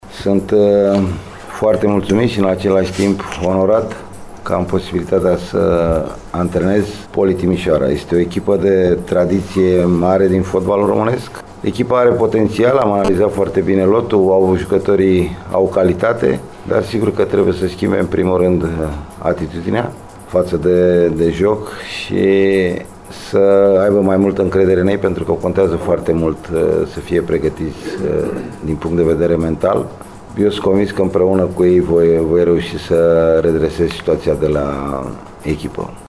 De altfel, o bună parte a conferinţei de presă pentru prezentarea noului antrenor a fost prilej de regrete faţă de încheierea “mandatului Alexa”.